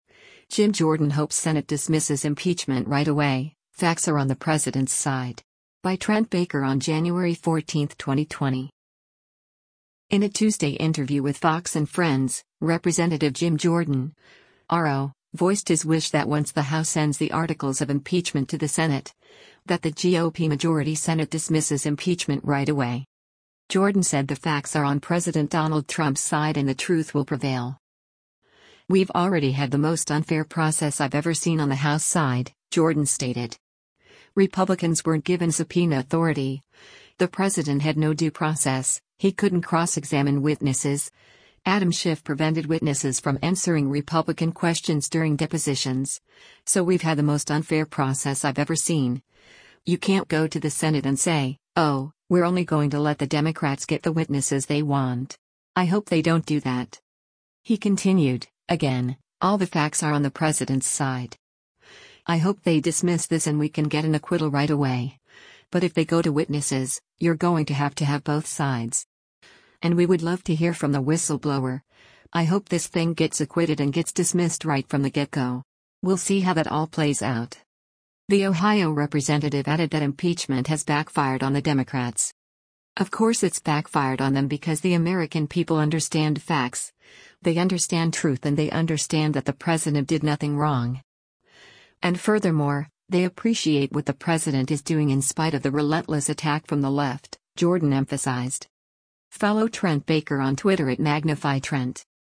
In a Tuesday interview with “Fox & Friends,” Rep. Jim Jordan (R-OH) voiced his wish that once the House sends the articles of impeachment to the Senate, that the GOP-majority Senate dismisses impeachment “right away.”